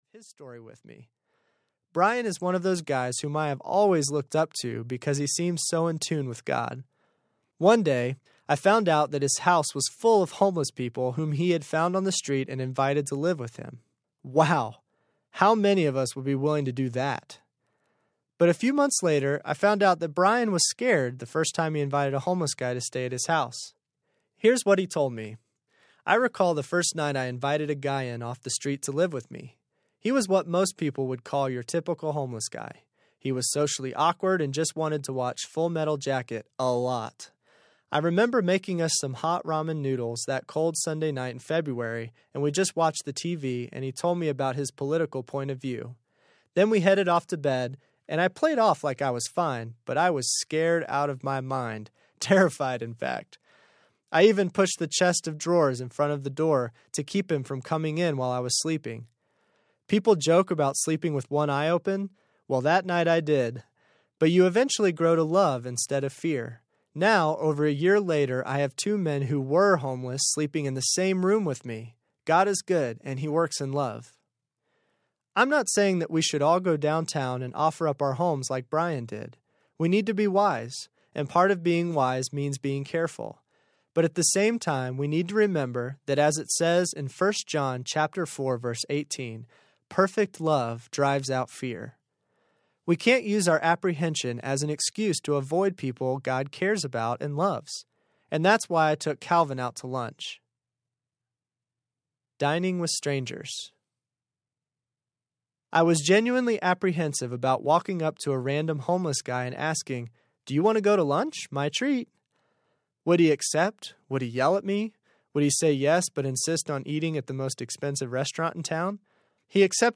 Ten Days Without Audiobook